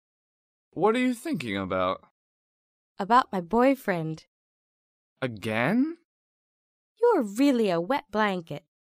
英语情景对话：